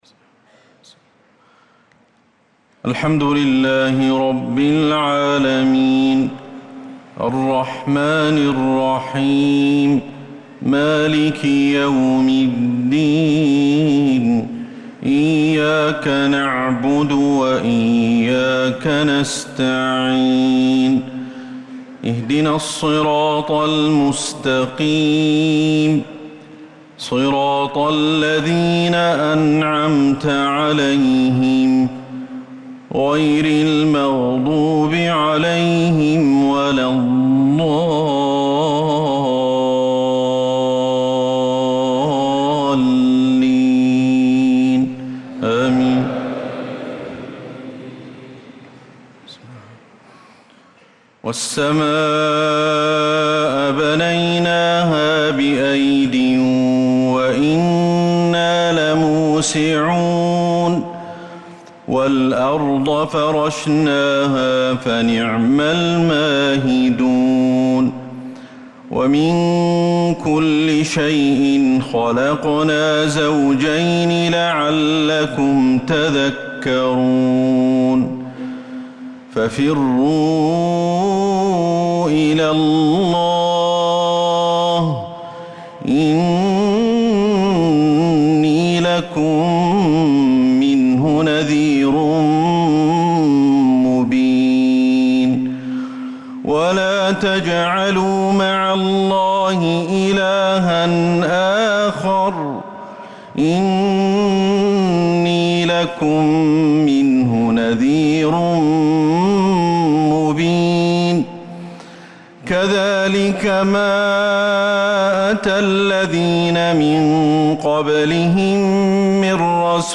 صلاة العشاء للقارئ أحمد الحذيفي 17 ذو الحجة 1445 هـ
تِلَاوَات الْحَرَمَيْن .